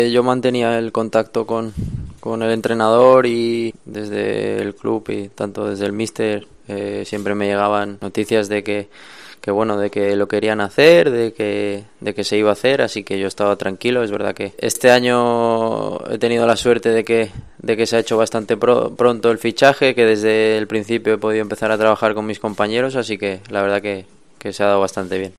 "El mister nos transmite que tenemos que exigirnos muchísimo, el Valencia es un club muy exigente y la afición, como tiene que ser, quiere lo máximo. El entrenador es el primero que quiere mejorar lo del año pasado y nosotros con él. Esperemos poder mejorarlo", dijo en la presentación de su fichaje tras haber estado cedido la pasada campaña.